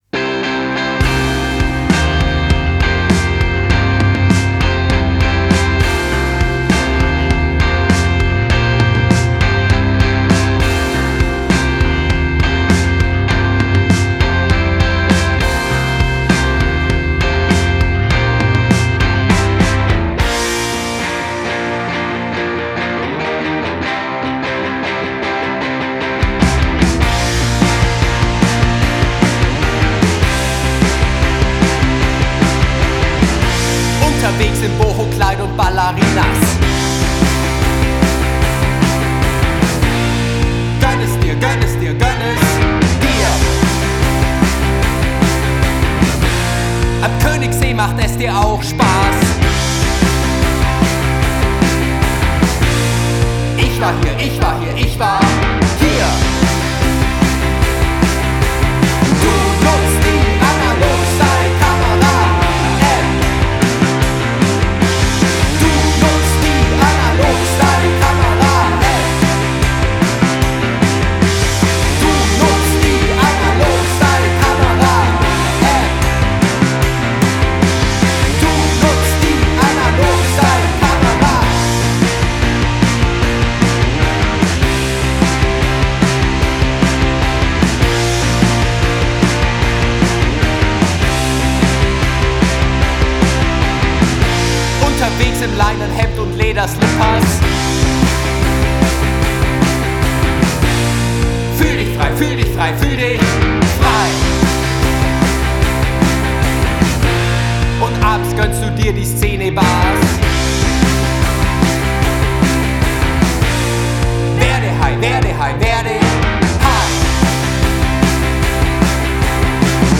Gitarre
Bass
Schlagzeug
Es wird wird laut, verrückt, schrammlig.